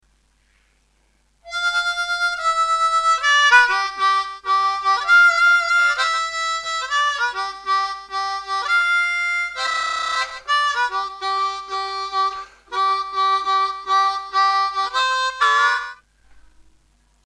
Full tab on a 10 hole C diatonic
5D   5B 4D   3D..2D   2D   2D..2D   (with hand vibrato)